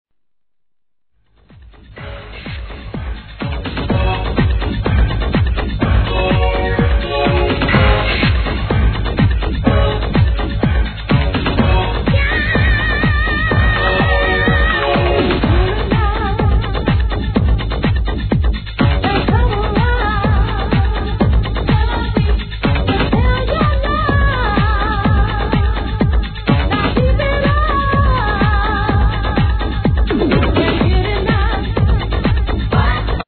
HIP HOP/R&B
当時人気のREMIXシリーズで、この盤オンリーMIX!!!!